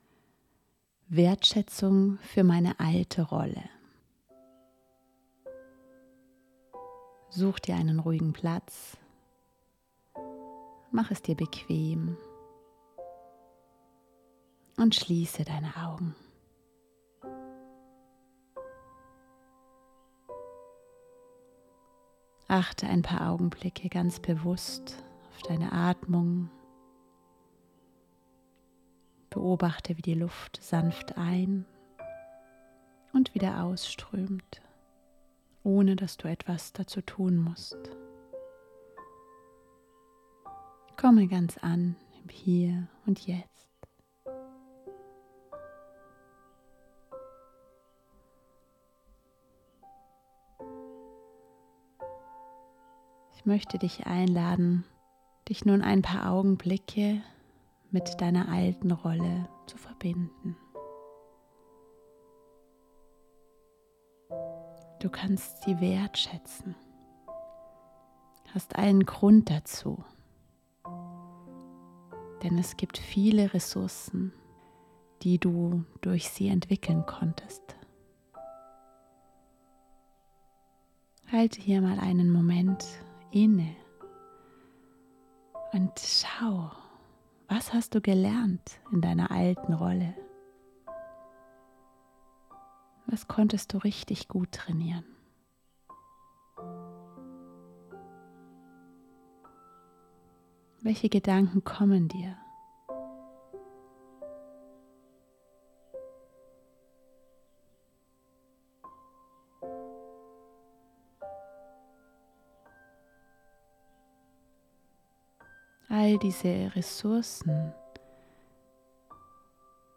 Gedankenreise: Wertschätzung